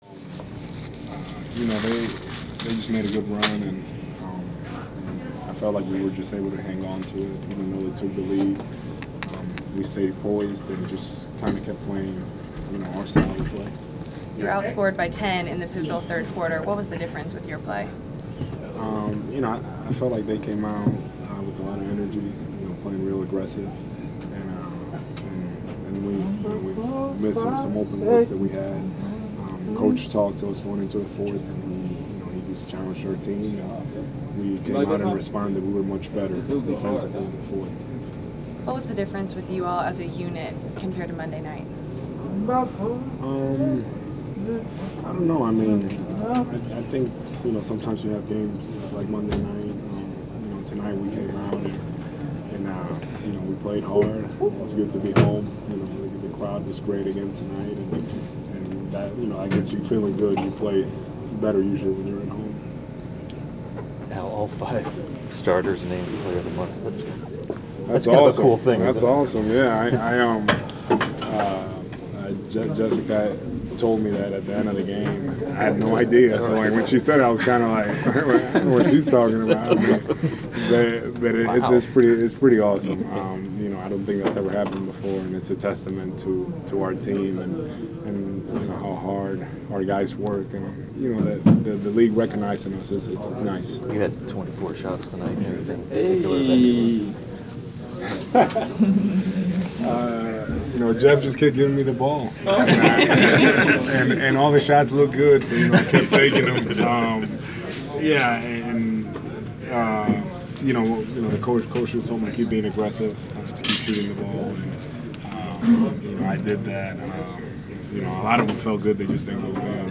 Inside the Inquirer: Postgame presser with Atlanta Hawks’ Al Horford (2/4/15)
We attended the postgame presser of Atlanta Hawks’ center Al Horford following his team’s 105-96 home victory over the Washington Wizards on Feb. 4.
horford-post-washington.wav